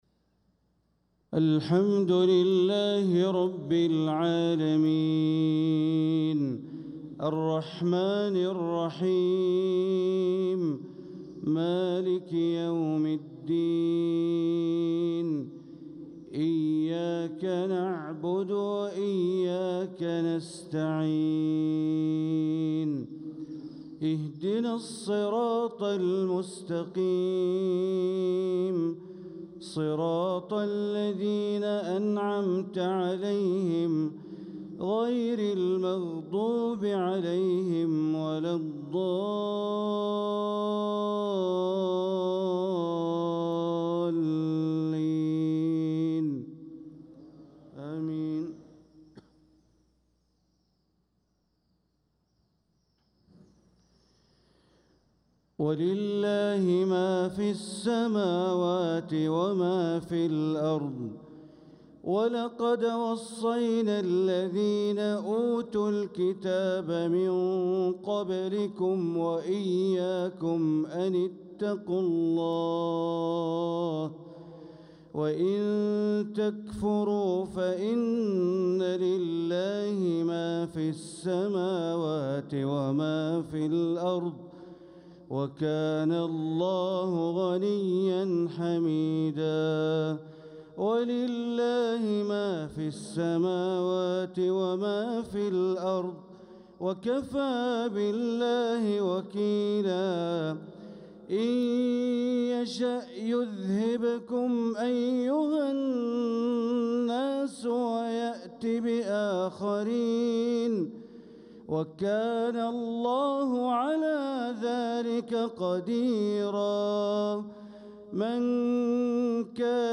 صلاة المغرب للقارئ بندر بليلة 1 ربيع الآخر 1446 هـ
تِلَاوَات الْحَرَمَيْن .